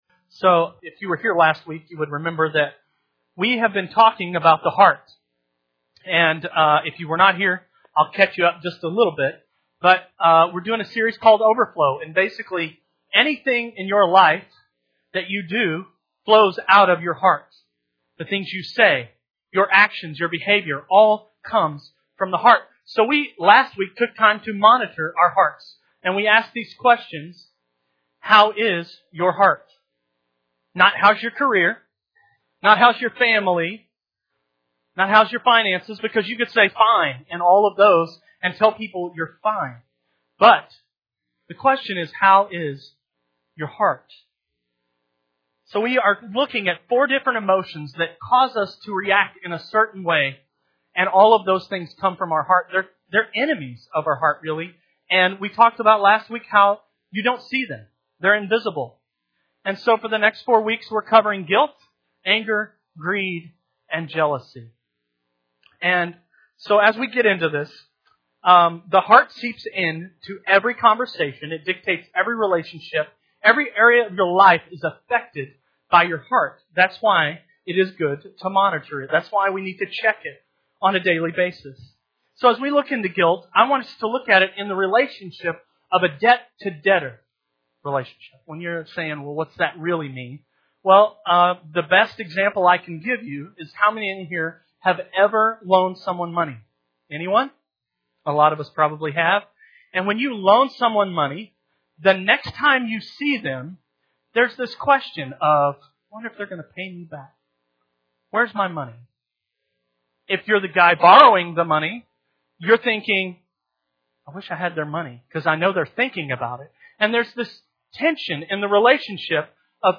Sunday Morning Service
Sermon